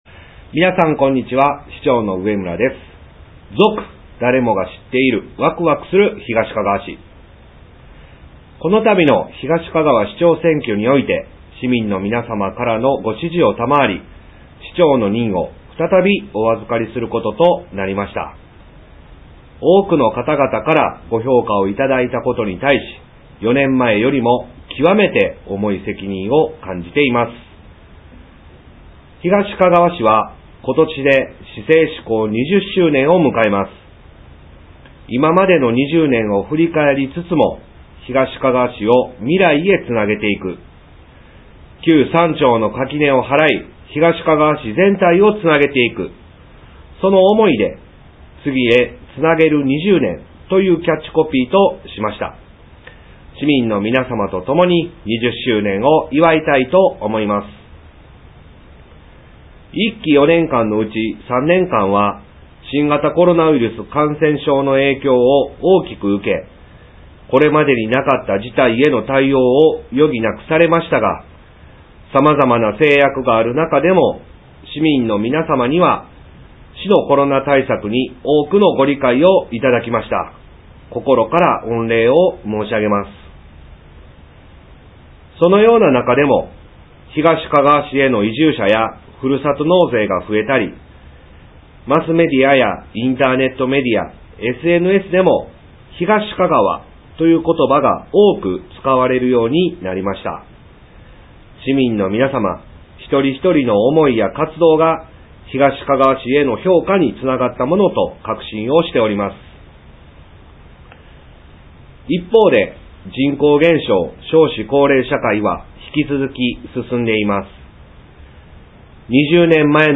就任のごあいさつ(音声ファイル:1018.9KB)